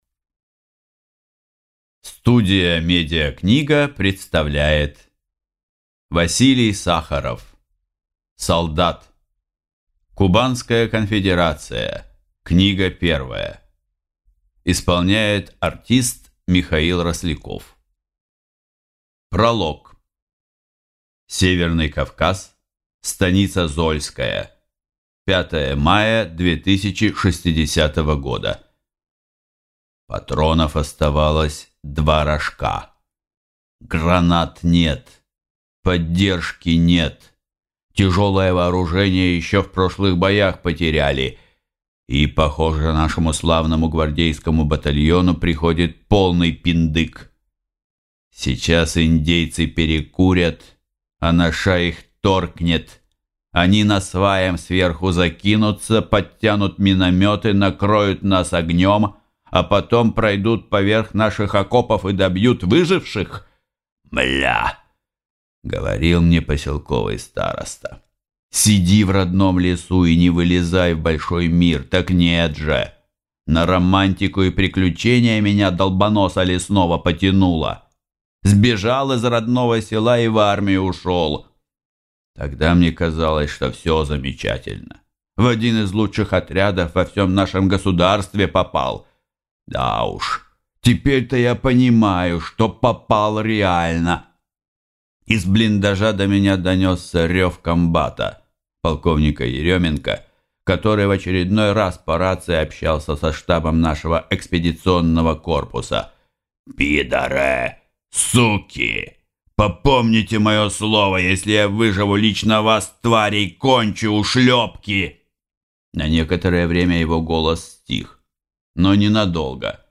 Аудиокнига Солдат | Библиотека аудиокниг